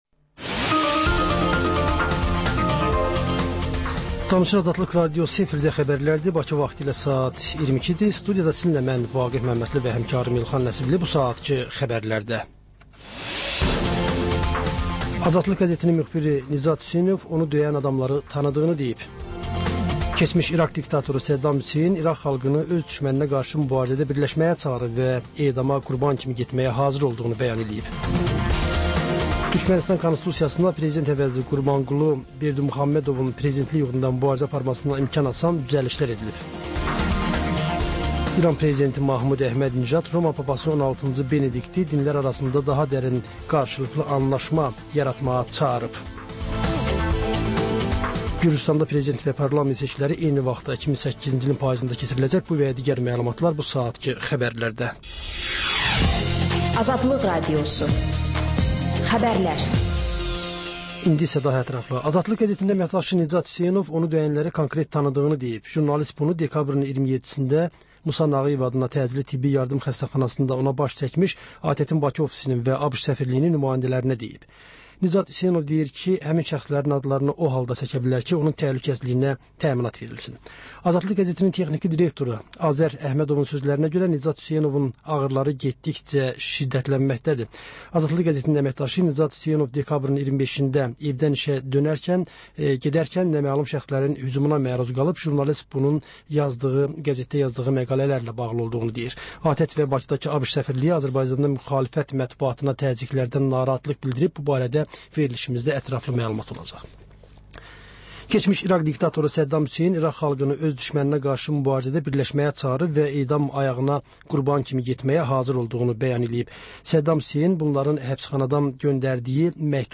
Xəbər, reportaj, müsahibə. Sonra: 14-24: Gənclərlə bağlı xüsusi veriliş.